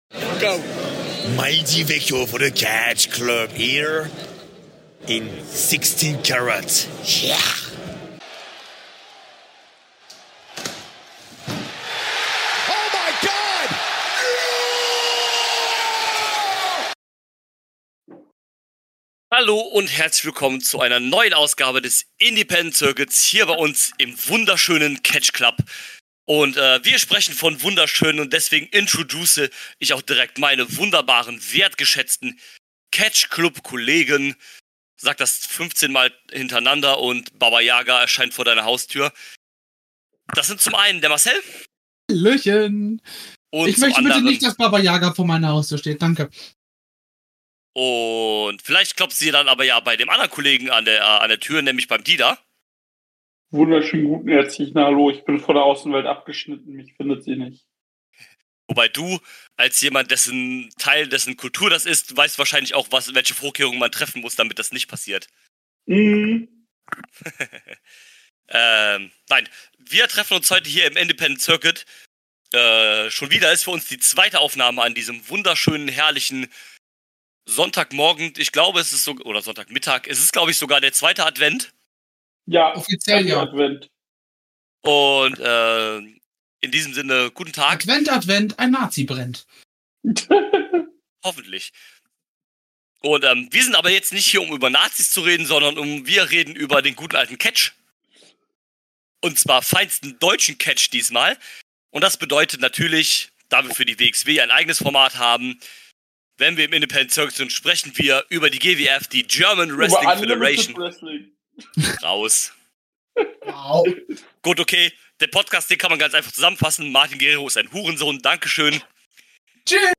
Die waren mit Final Countdown wieder im Catchsaal Kreuzberg. Zu 3. sind wir auf die Show eingegangen